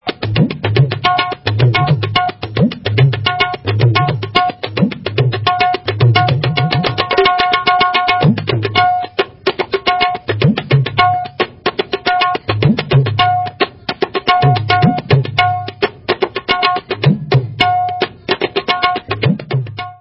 Tabla Filmi